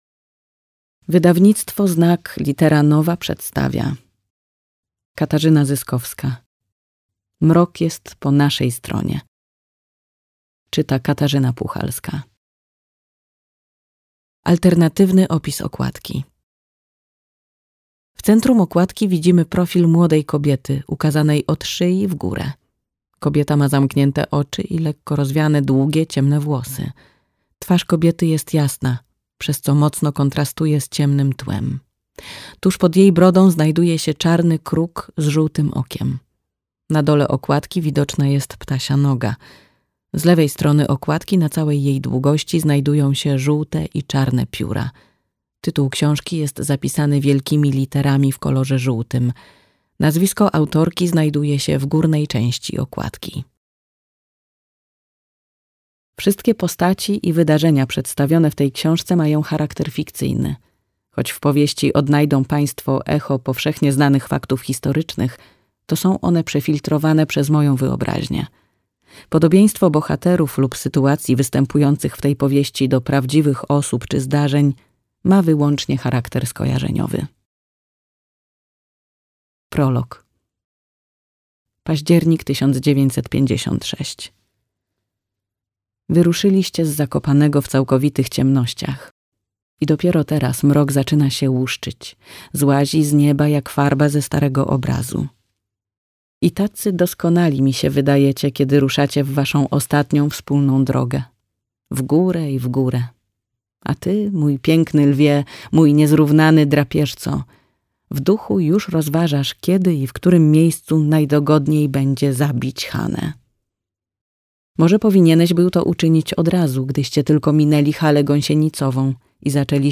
Audiobook + książka Mrok jest po naszej stronie, Katarzyna Zyskowska.